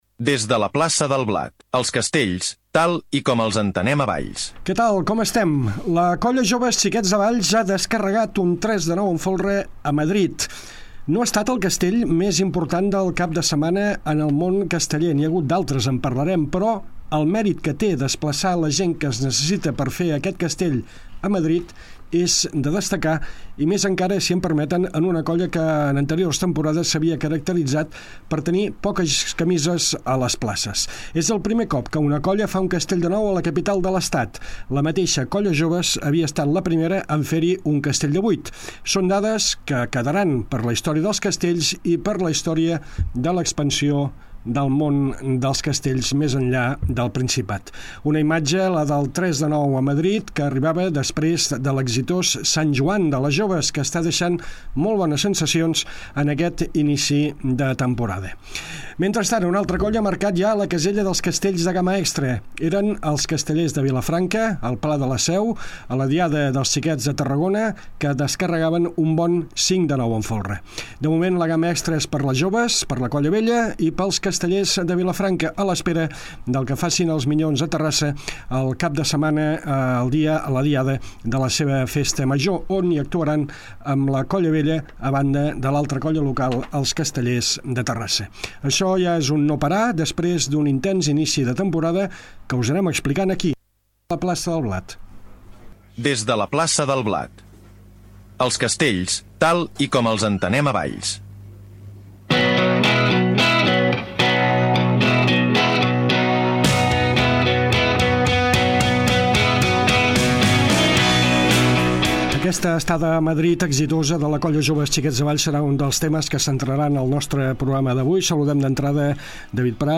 Tercer programa de Des de la Plaça del Blat, el programa que explica els castells tal com els entenem a Valls. Tertúlia